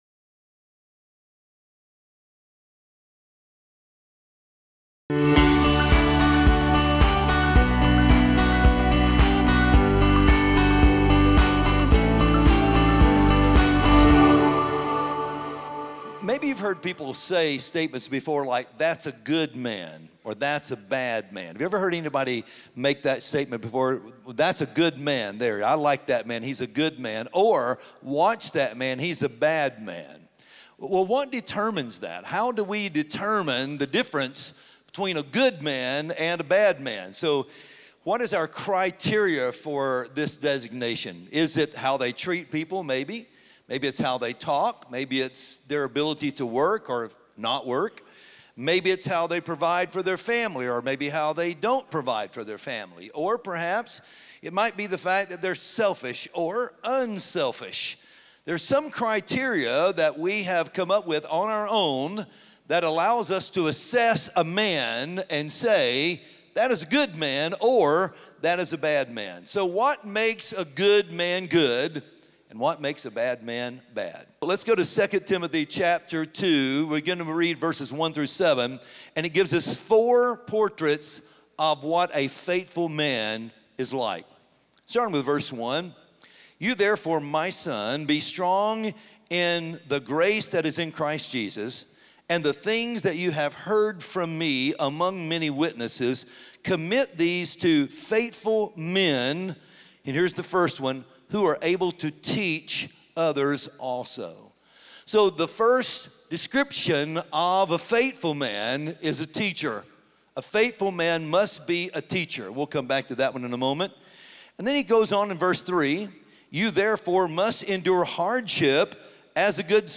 Join us this week for the sermon “Real Men Listen.”